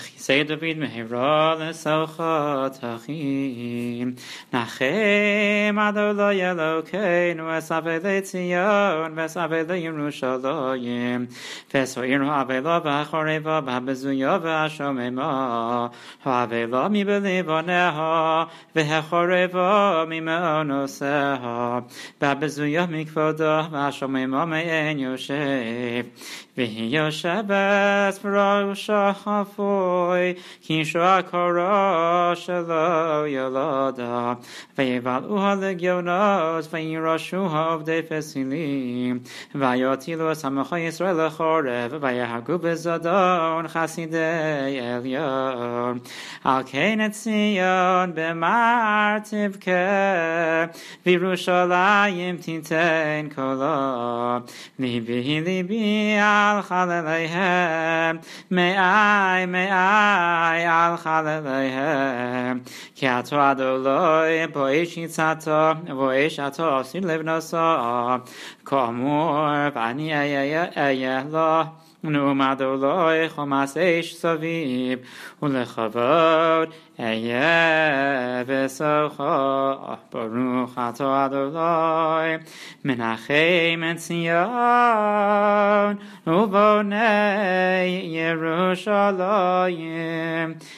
Nusach